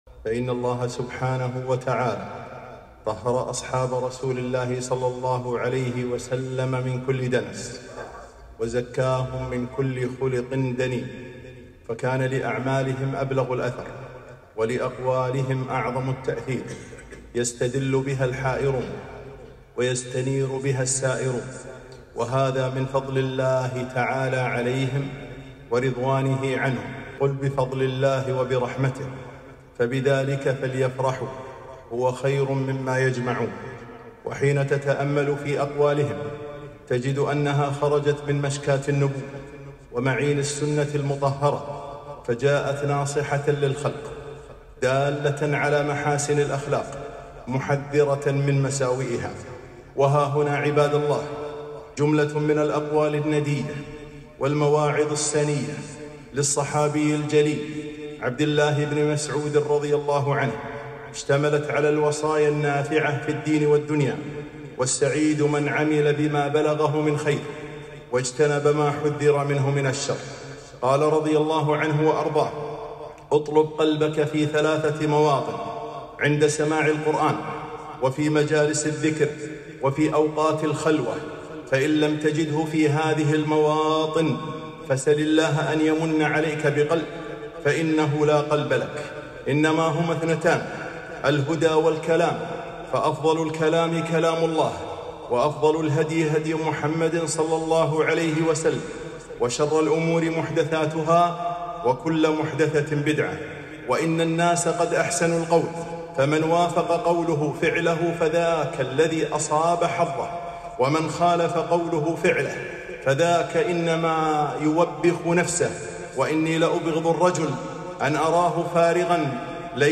خطبة - من مواعظ عبدالله بن مسعود رضي الله عنه